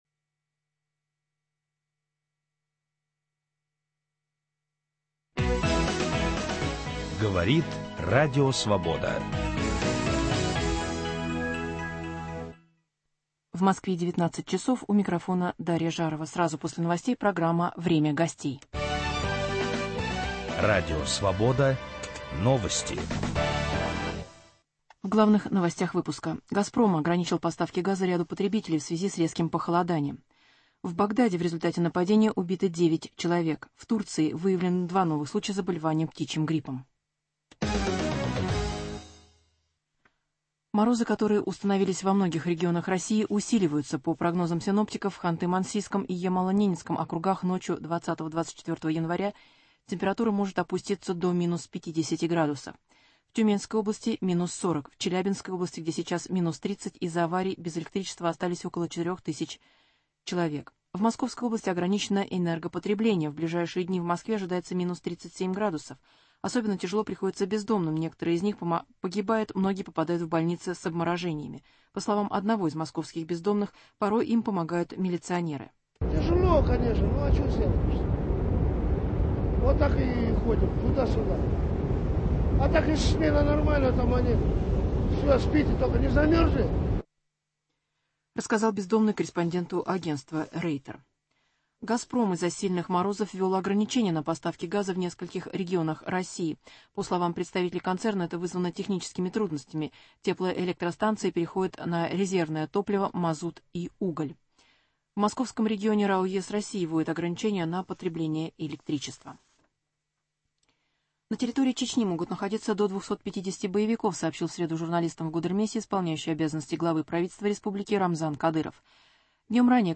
Известные и интересные люди ведут разговор о стране и мире, отвечают на вопросы в прямом эфире. Круг вопросов - политика, экономика, культура, права человека, социальные проблемы.